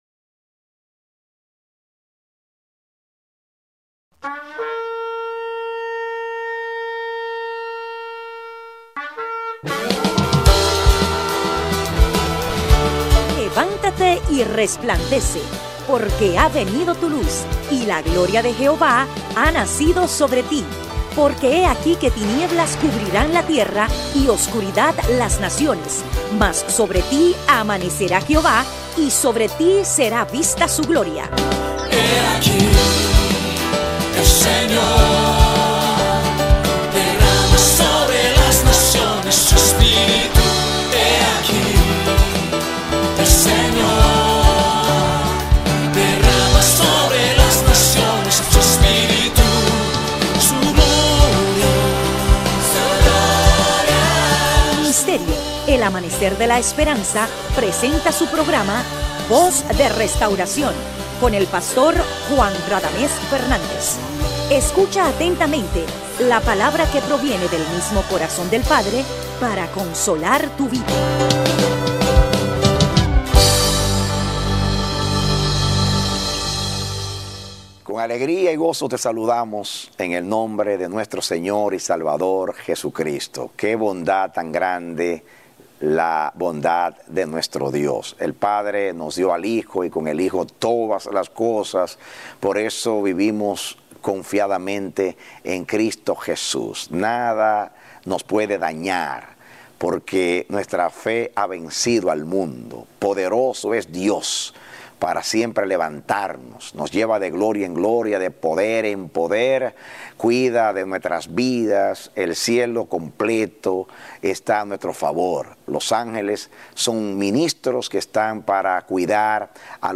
Mensajes TVs – El Amanecer de la Esperanza Ministry